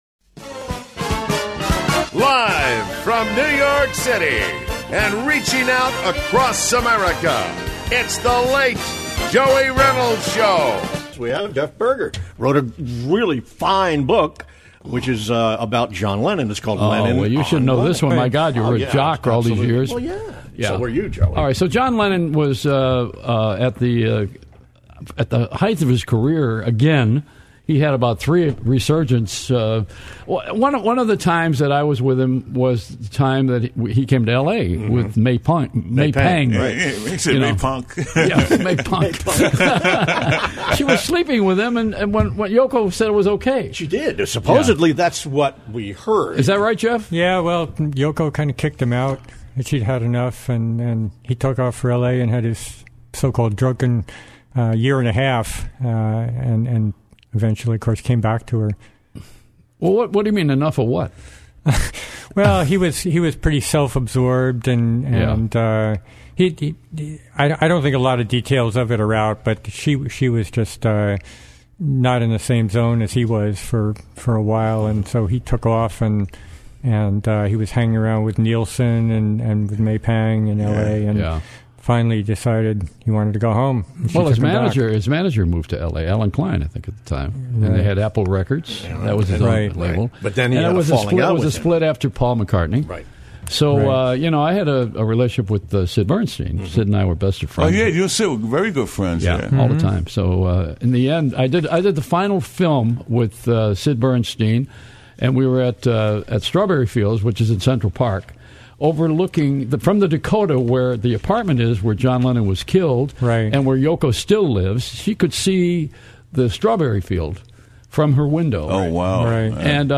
Dec. 18, 2016—live radio interview (WABC, New York; KABC, Los Angeles). Discussion of Lennon on Lennon, The Late Joey Reynolds Show.